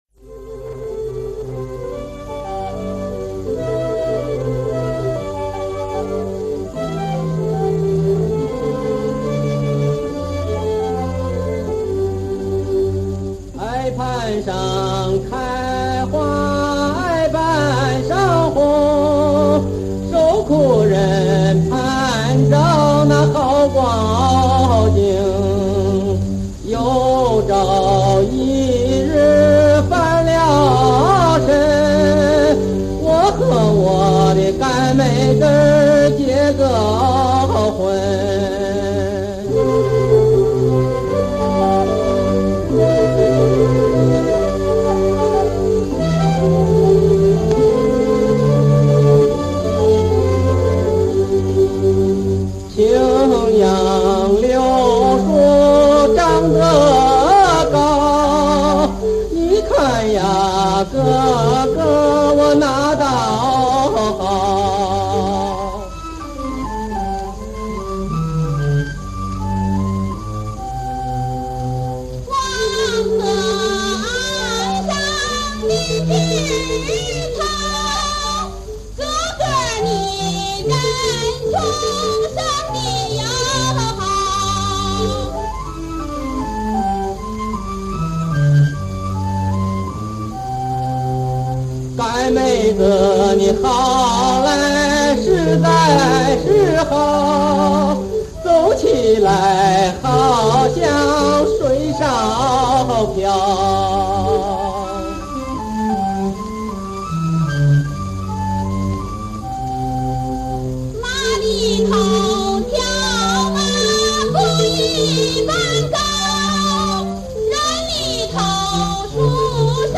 从这首歌，我们可以感受到老一代艺术家在当时的伴奏、录音条件下 演唱的风采。